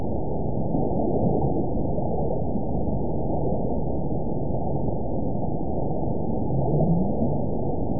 event 922877 date 04/30/25 time 16:33:47 GMT (1 day, 2 hours ago) score 9.43 location TSS-AB01 detected by nrw target species NRW annotations +NRW Spectrogram: Frequency (kHz) vs. Time (s) audio not available .wav